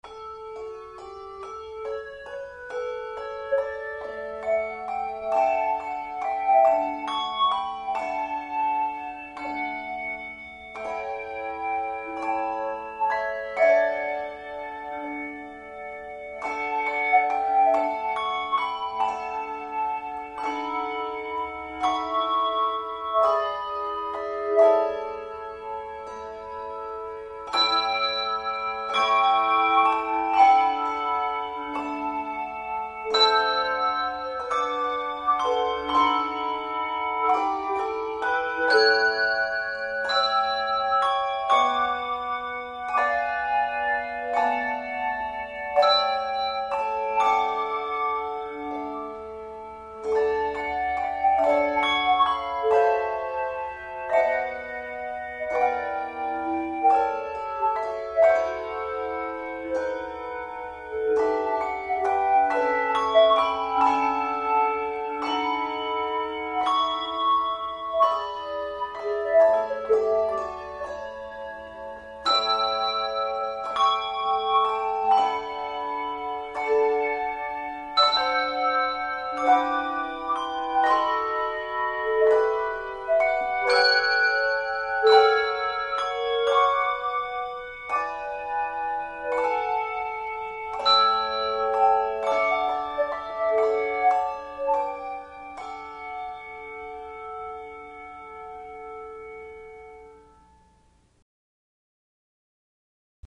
Handbell trio or quartet